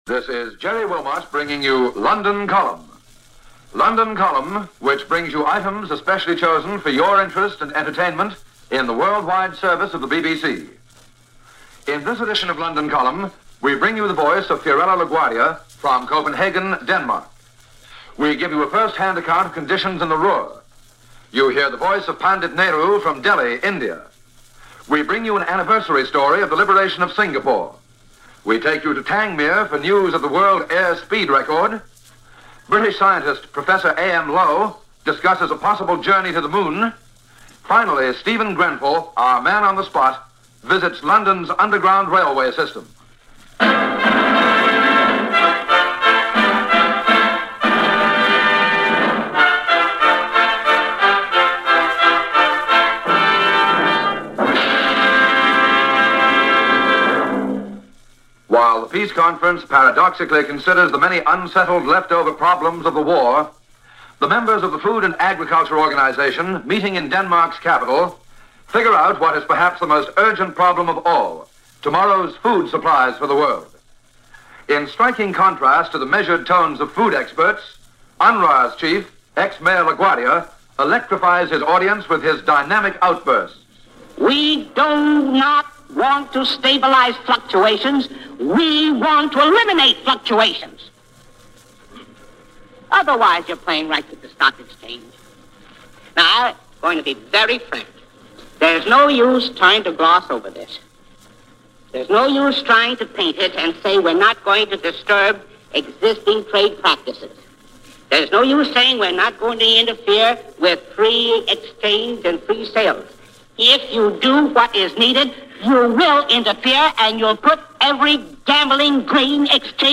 World After War - Recovery, Recollections And Maybe The Moon - September 11, 1946 - Report from London Column - BBC World Service